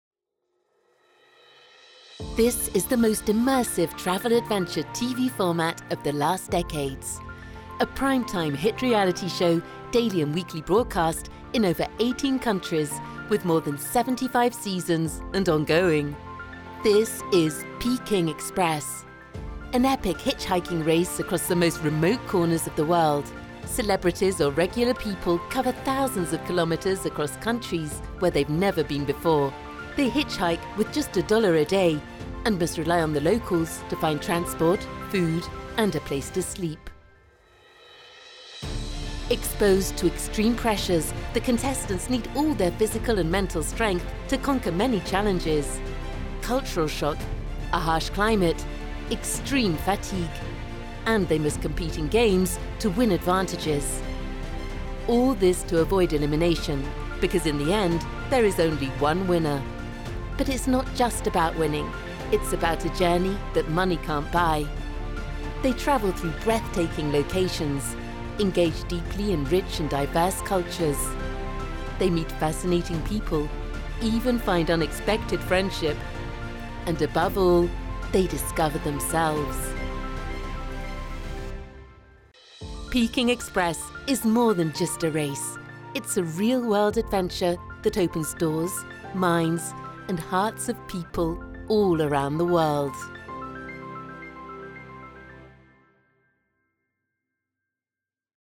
Voice Over & DocumentariesVoix In & documentaires
TRAILER PEKING EXPRESS